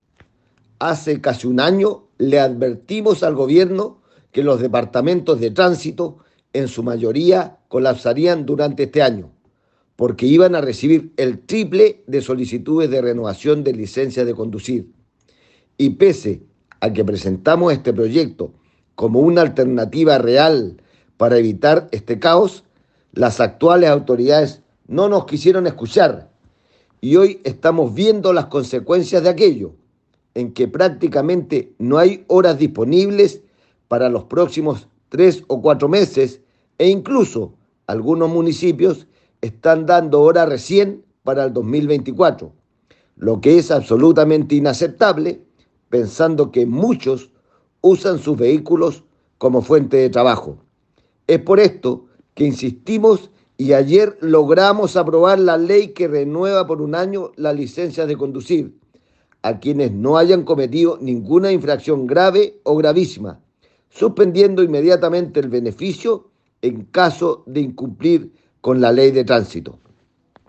Al respecto el legislador señaló:
FERNANDO-BORQUEZ.m4a